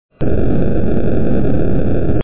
Diese klassisch unmögliche Rauschunterdrückung des Quantenlichts eröffnet neue Wege zur Analyse von Signalen.